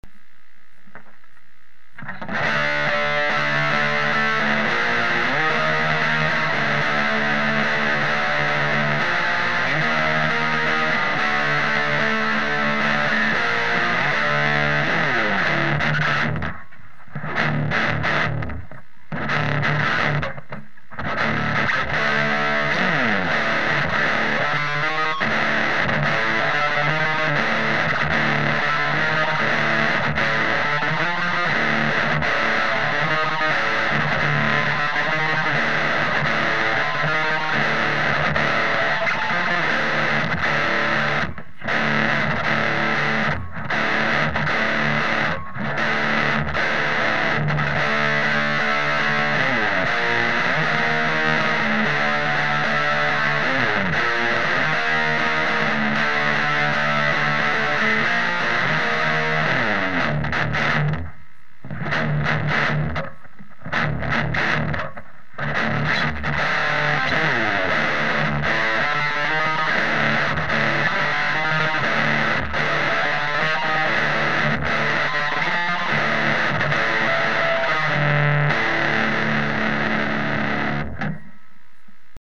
No effects were used either in the recording or mix down.
For this stuff, every Button on the TMB channel was on 10.